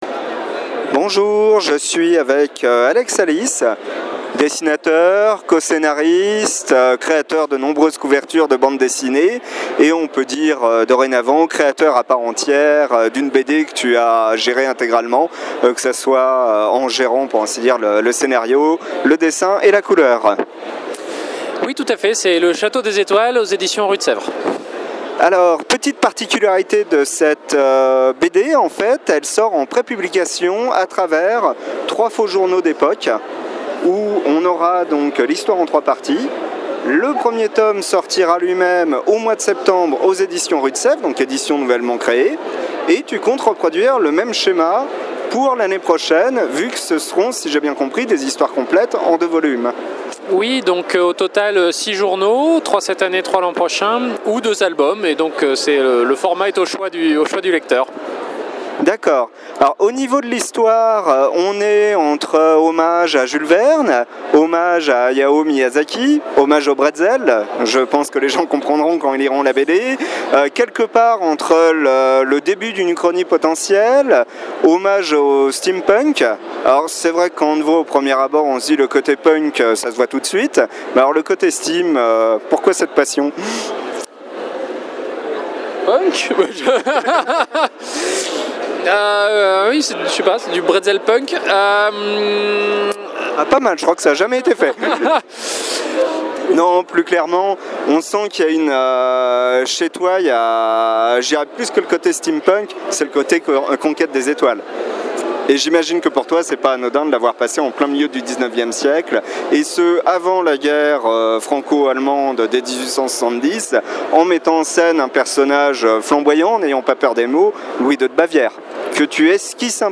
Interview Alex Alice pour le Chateau des étoiles.